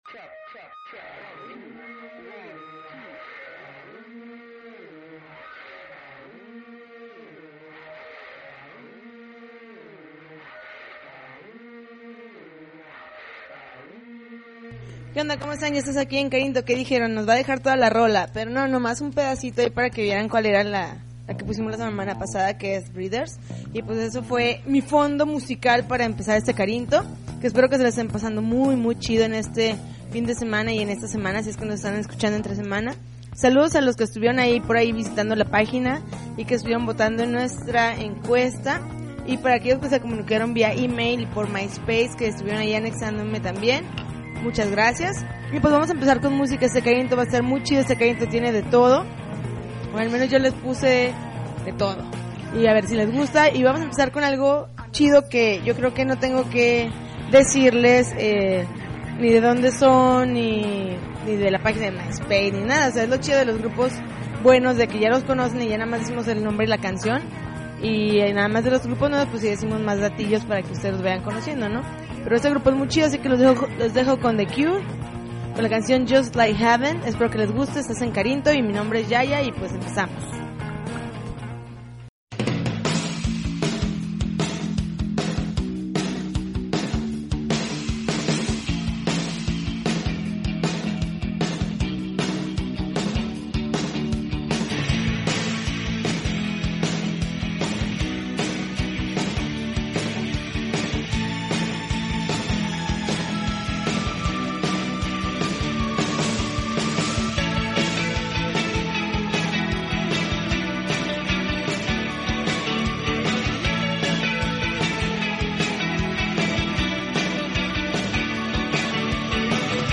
March 29, 2009Podcast, Punk Rock Alternativo